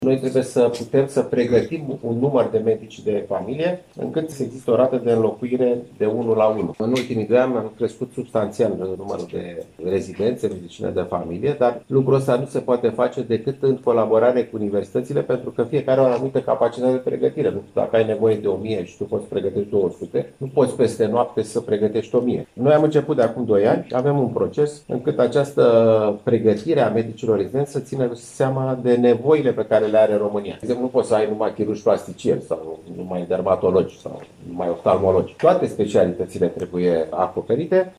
Ministrul Sănătății, Alexandru Rafila, a declarat astăzi, la Iași, că în următorii cinci ani, peste 11.000 de medici de familie din România vor ieși la pensie, iar gradul de ocupare va fi unul foarte scăzut în acest domeniu.